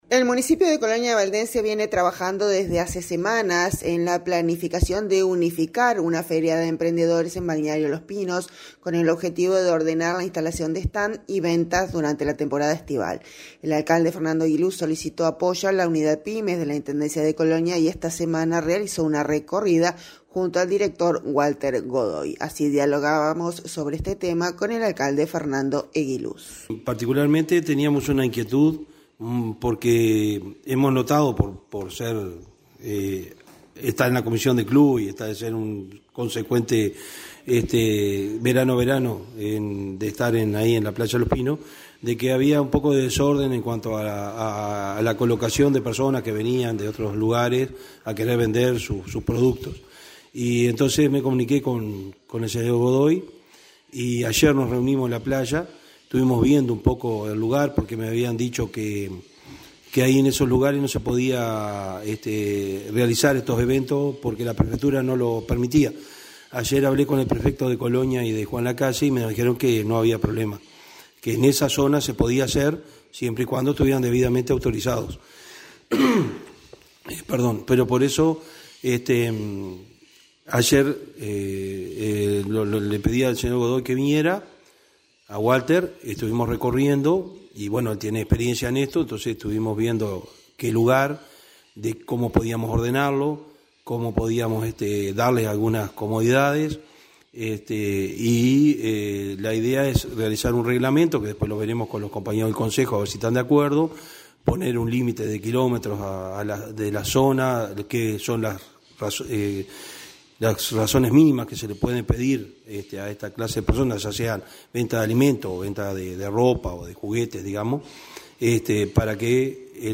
Así dialogábamos sobre este tema con el Alcalde Fernando Eguiluz.